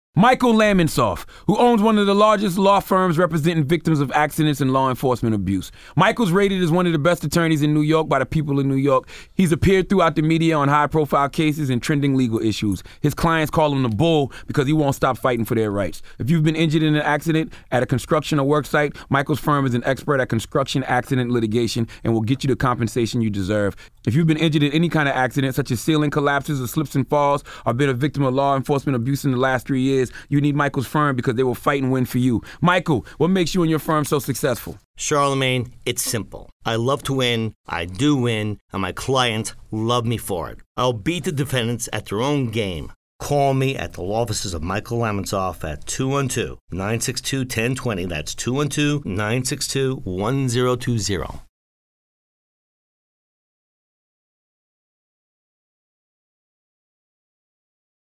Radio Interviews
on the air with Angie Martinez on Power 105.1